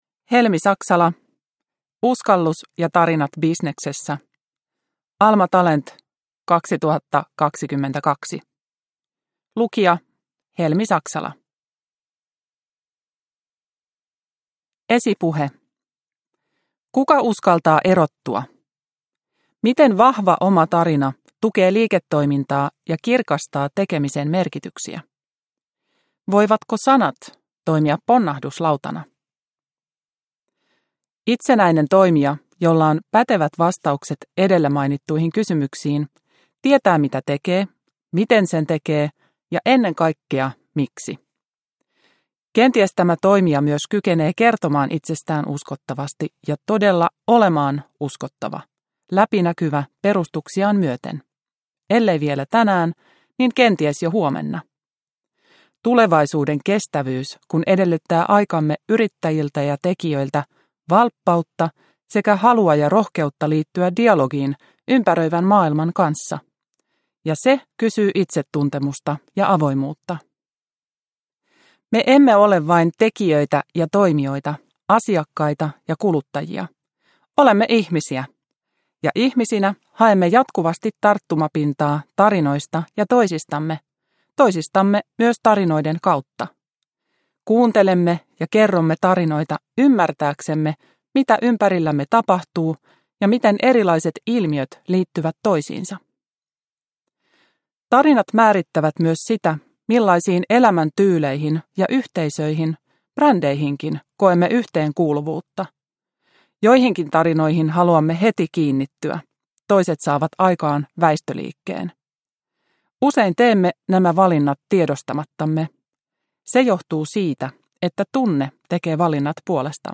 Uskallus ja tarinat bisneksessä – Ljudbok – Laddas ner